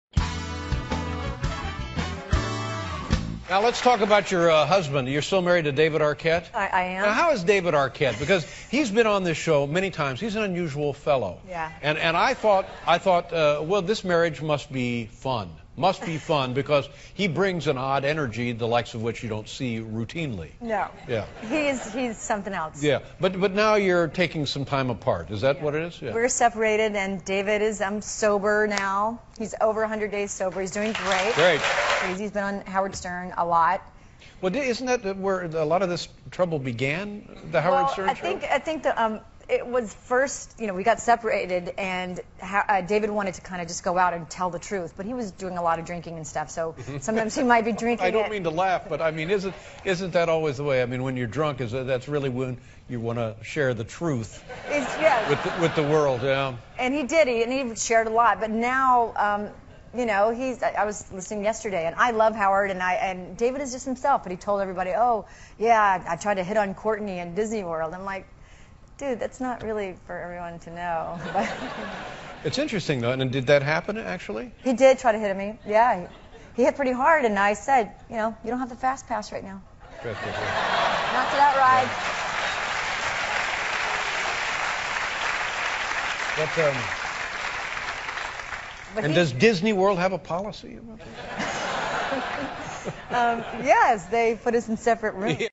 访谈录 2011-04-16&04-18 柯特妮·考克斯(老友记主演 听力文件下载—在线英语听力室